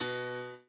b_piano1_v100l4o4b.ogg